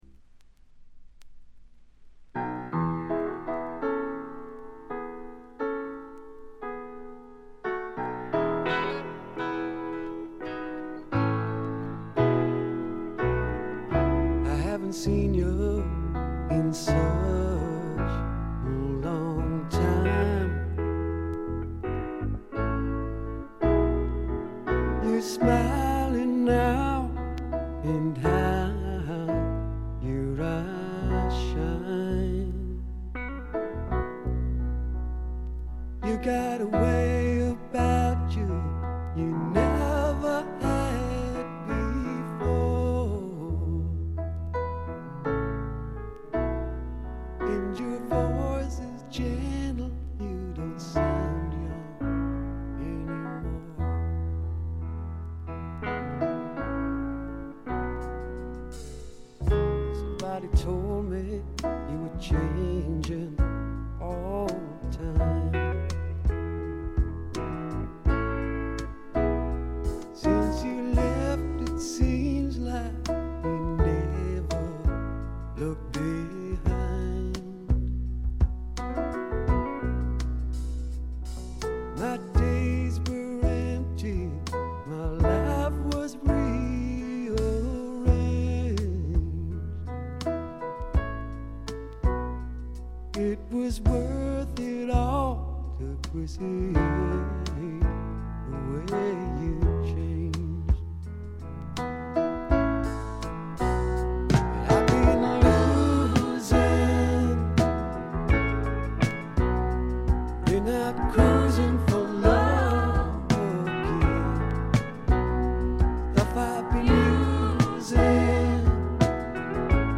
ところどころでチリプチ。
内容は身上である小気味良いロックンロール、軽快なフォークロック、メロディアスなポップ作等バラエティに富んだもの。
試聴曲は現品からの取り込み音源です。